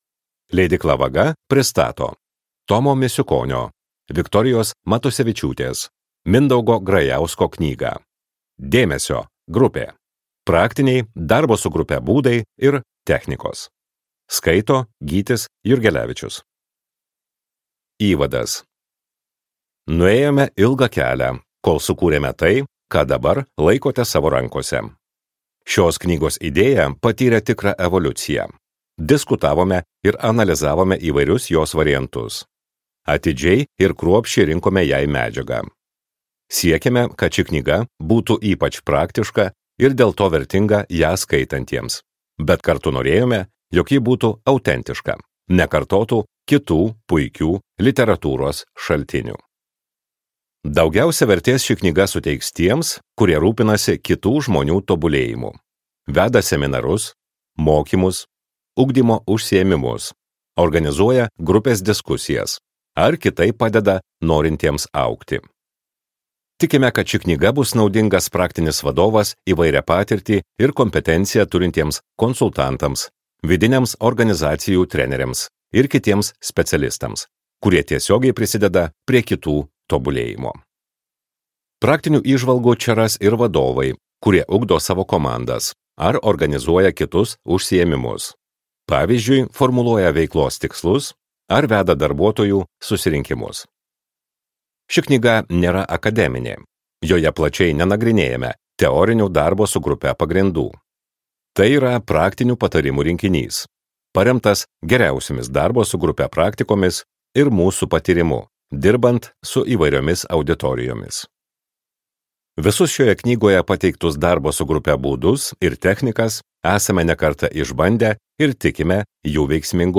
Skaityti ištrauką play 00:00 Share on Facebook Share on Twitter Share on Pinterest Audio Dėmesio, grupė!